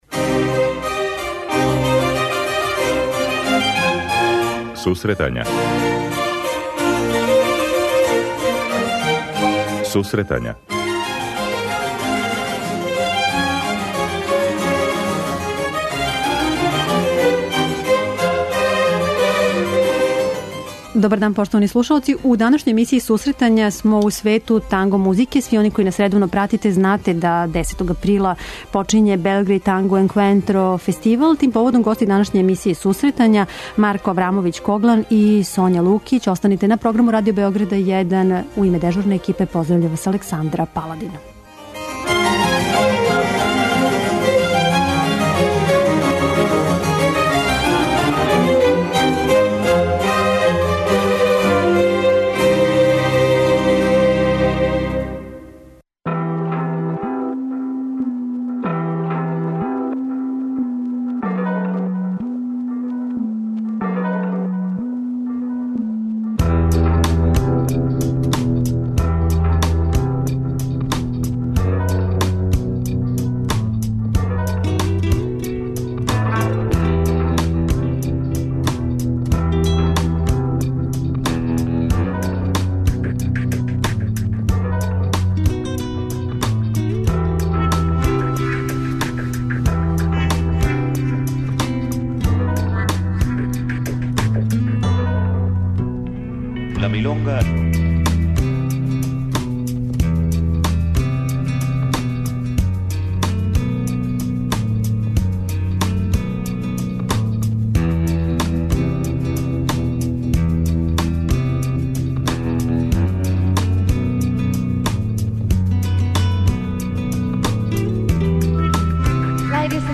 преузми : 25.56 MB Сусретања Autor: Музичка редакција Емисија за оне који воле уметничку музику.